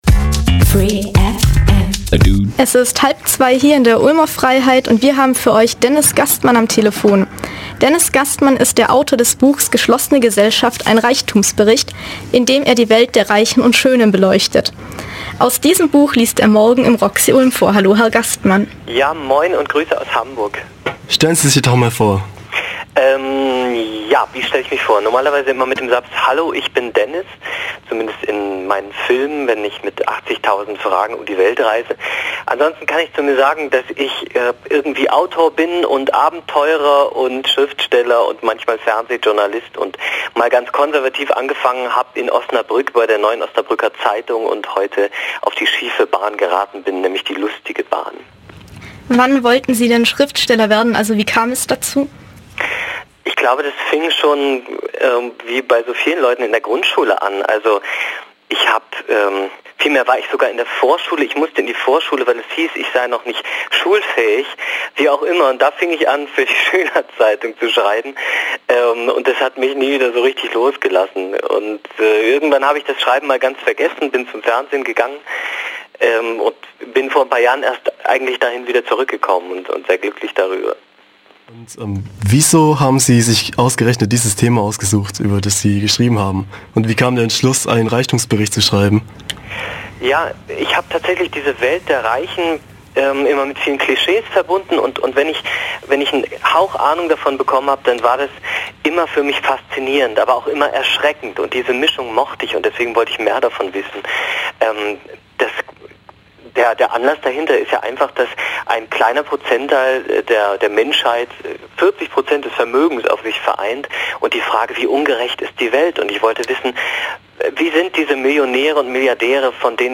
Interview mit Autor Dennis Gastmann
Am 9. Februar hatten wir Autor Dennis Gastmann für ein Interview am Telefon. Er tritt am 10. Februar um 20 Uhr im Roxy auf.